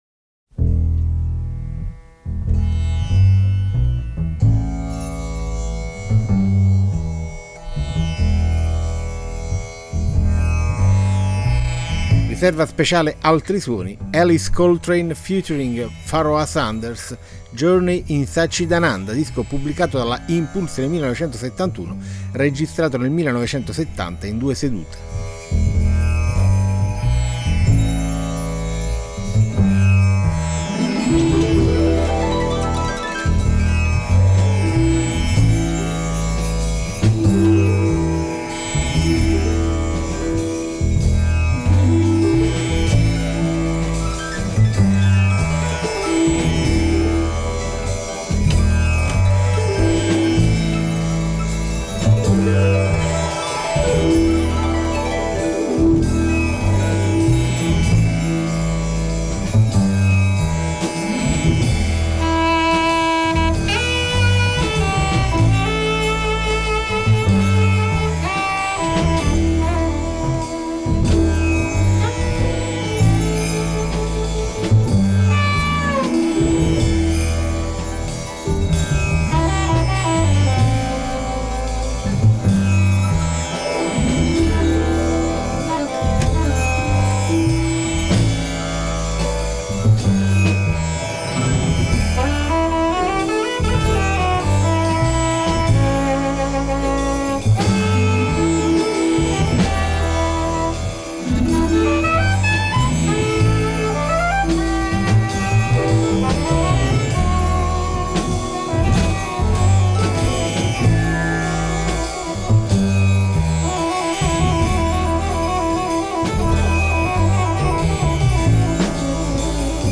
harp
bass